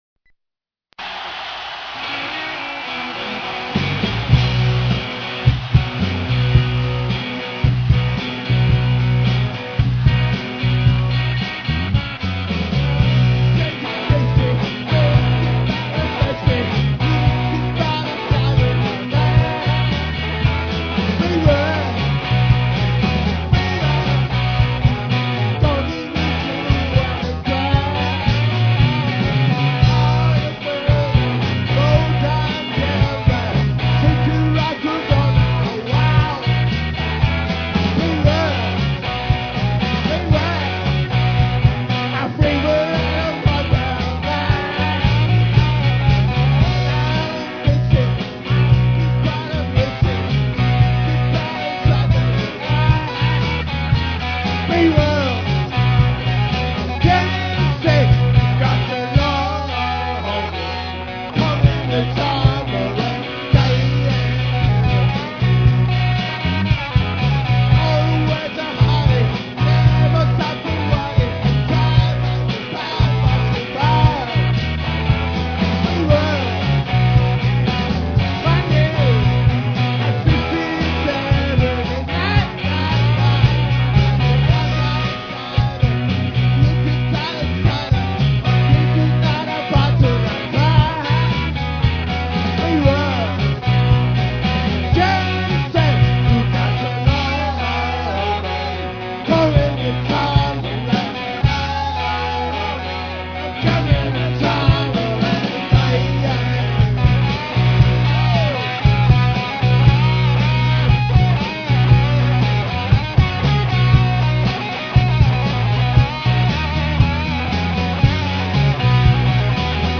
99.11月 ミントンハウス曲目